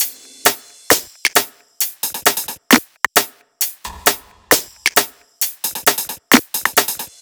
HP133BEAT3-R.wav